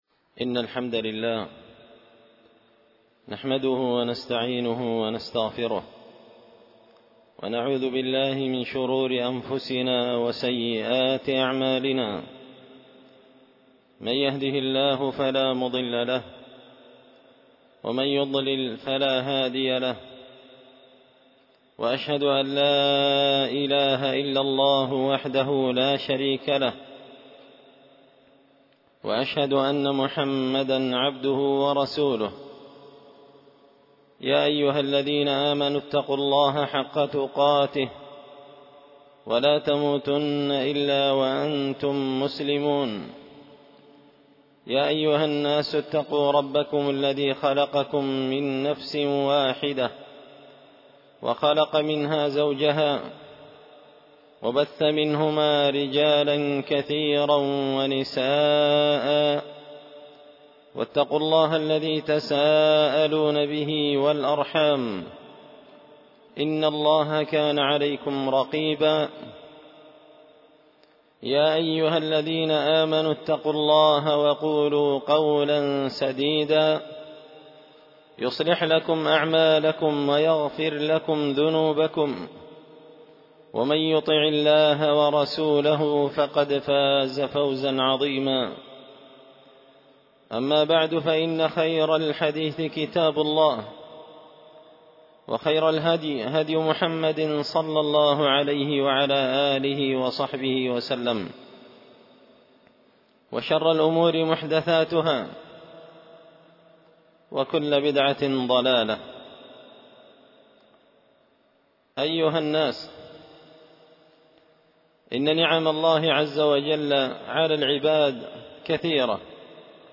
خطبة جمعة بعنوان – من ثمرات شكر الله تعالى
دار الحديث بمسجد الفرقان ـ قشن ـ المهرة ـ اليمن